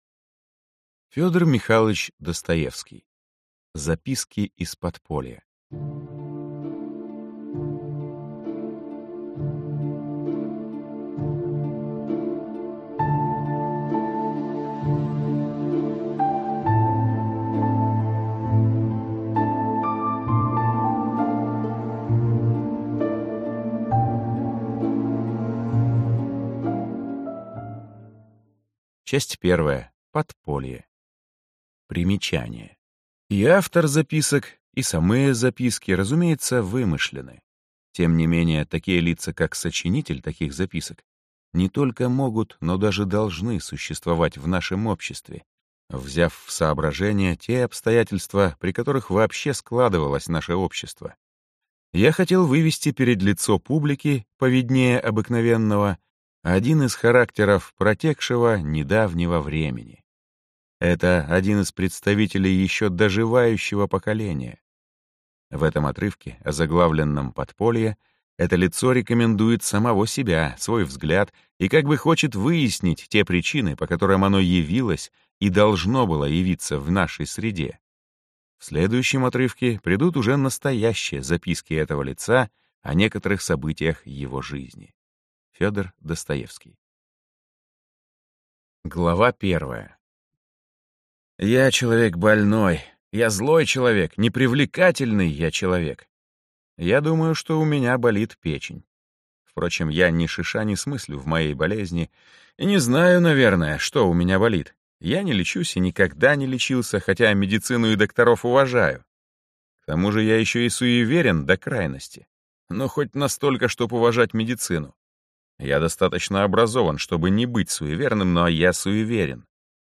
Аудиокнига Записки из подполья. Вечный муж. Бобок | Библиотека аудиокниг